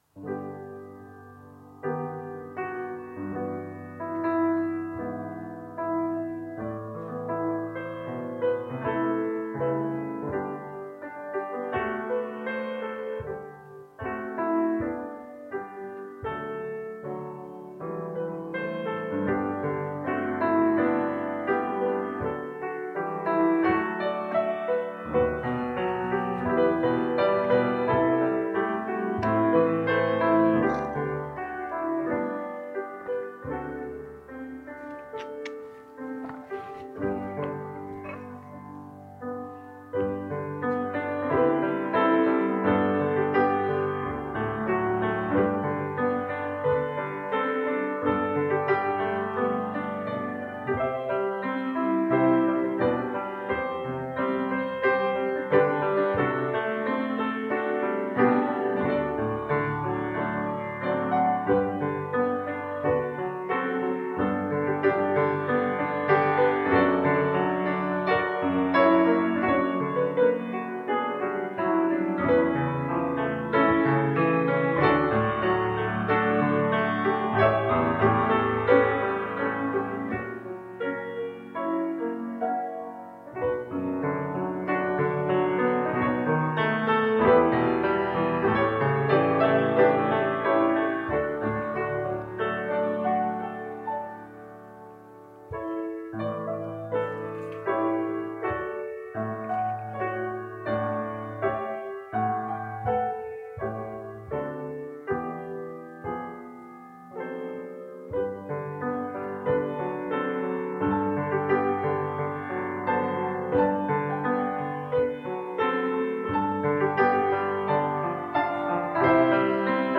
Part of our audio toward the end of the prayer was compromised; we salvaged what we could, and apologize for not being able to share the entirety of the music.